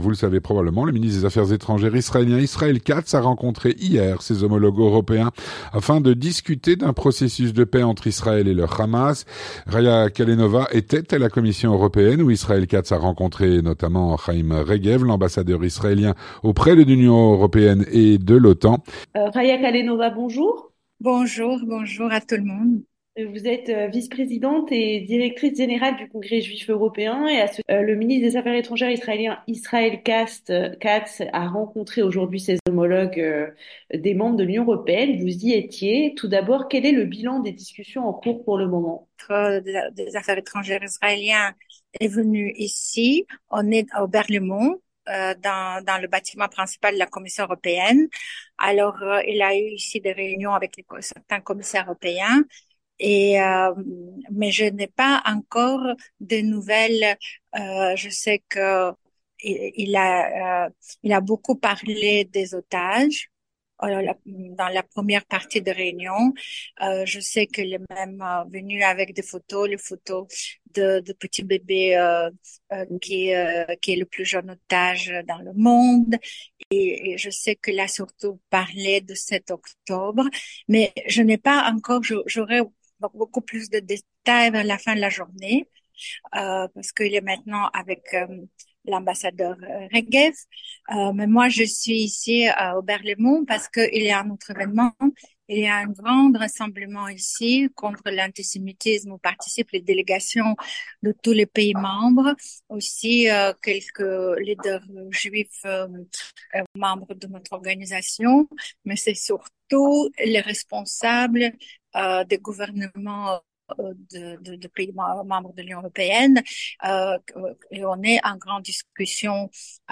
L'entretien du 18H - Israël Katz a rencontré ses homologues européens, hier à Bruxelles.